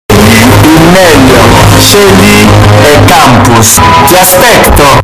ti-aspecto-extreme-earrape_HgaOdon.mp3